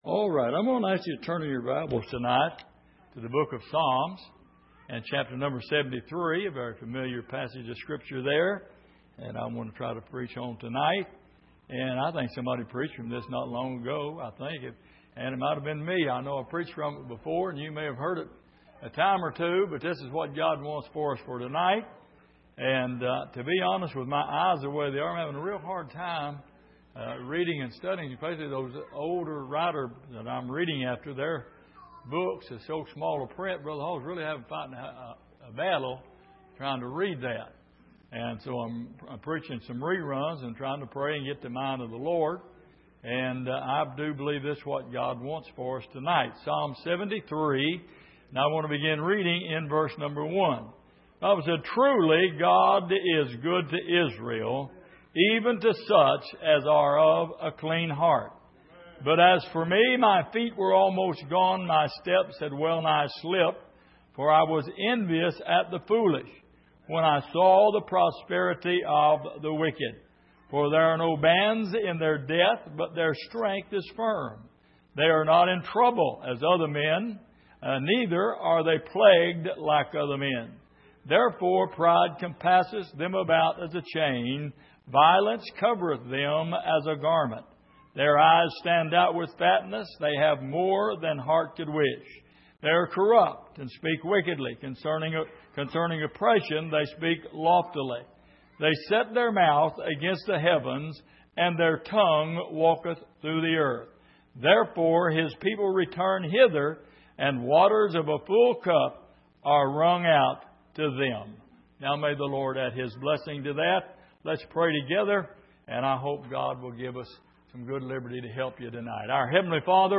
Passage: Psalm 73:1-10 Service: Midweek